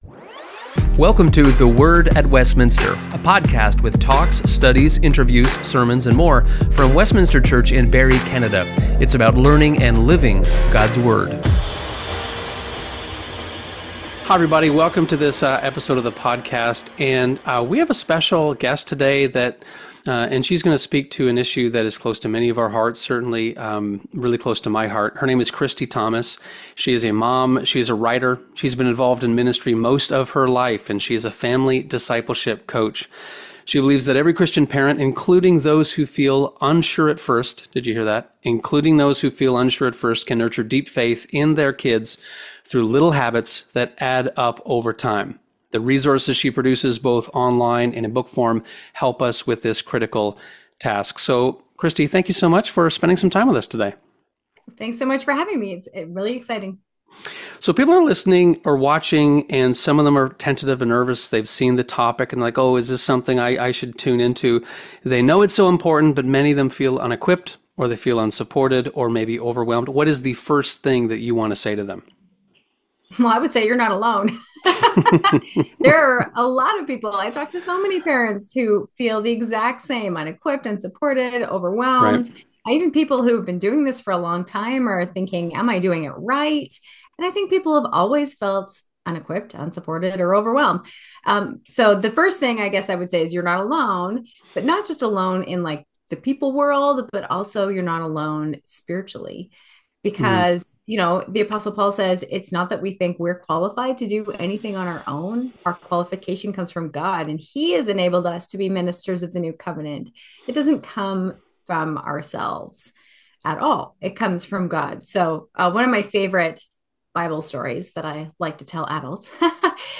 It is down-to-earth, fun, and theological at the same time.